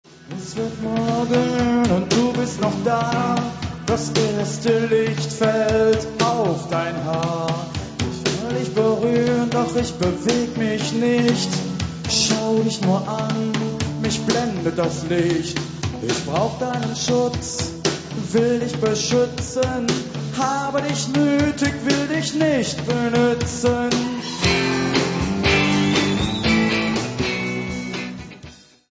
Neuen Deutschen Welle